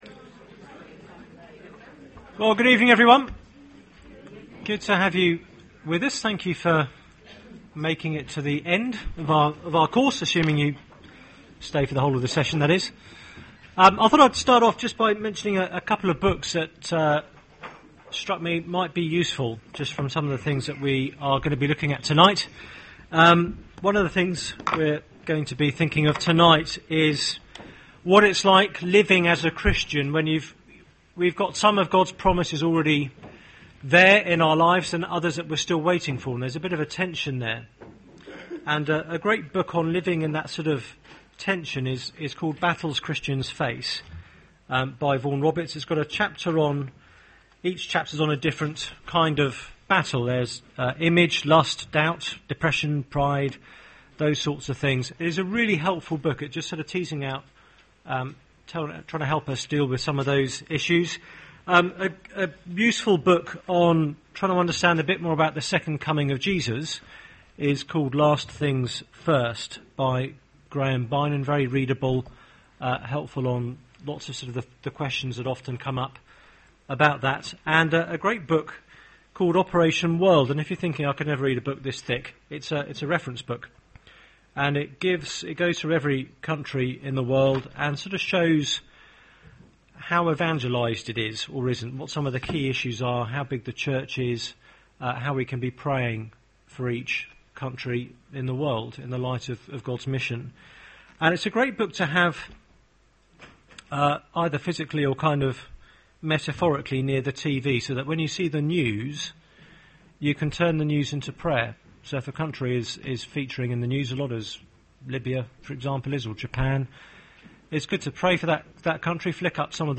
Media for Seminar on Tue 05th Apr 2011 20:00 Speaker